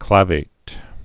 (klāvāt)